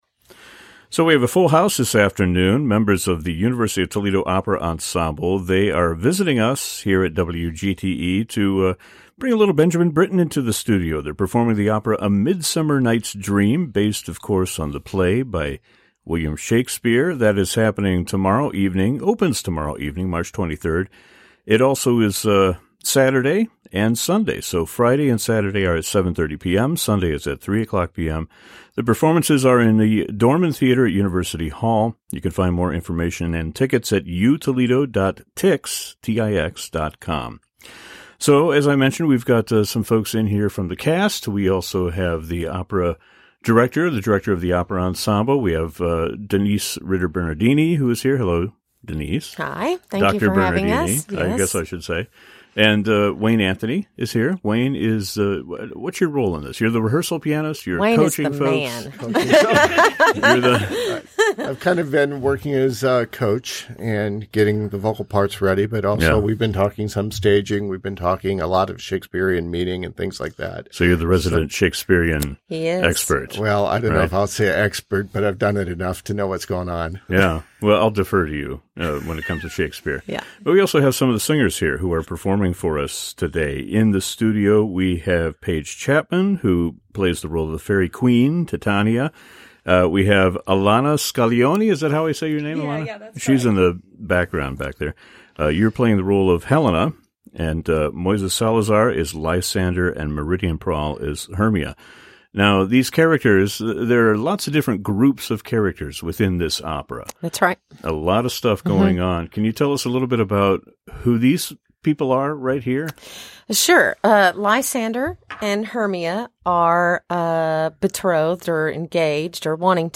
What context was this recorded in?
Please note: due to copyright, musical selections have been edited out of this interview.